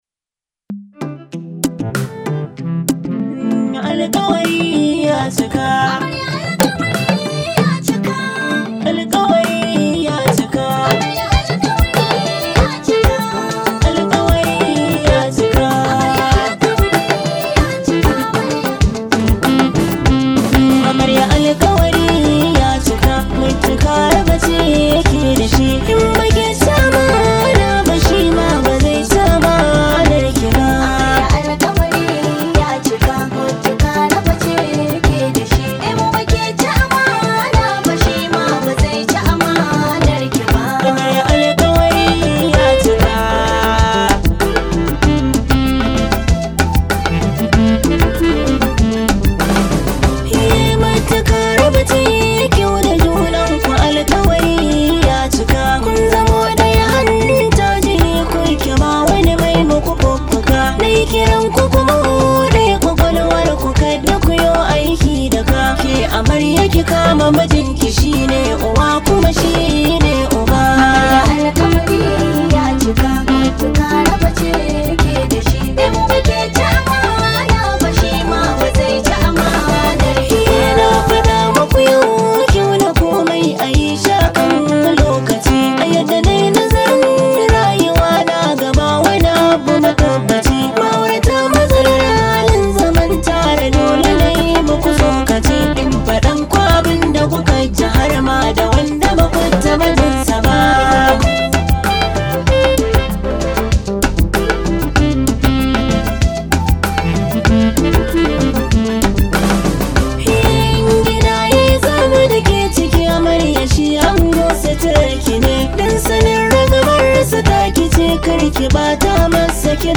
Hausa Musics